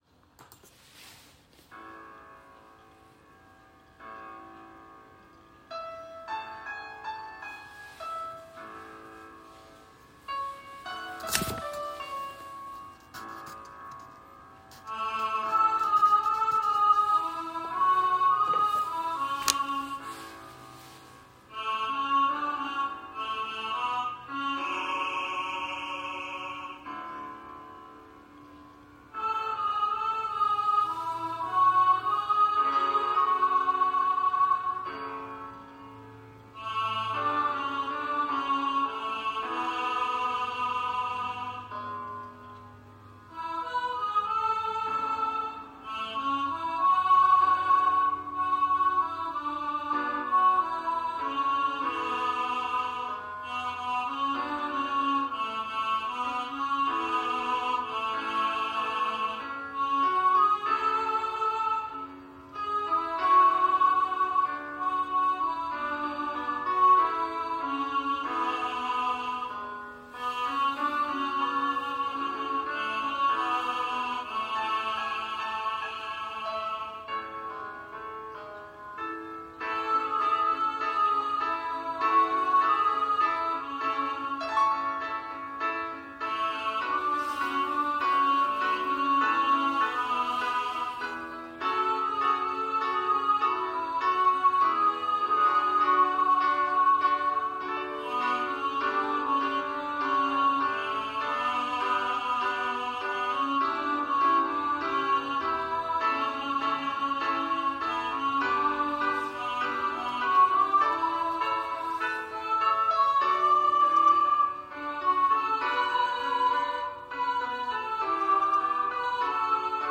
This is a piano reduction.
Voicing/Instrumentation: SATB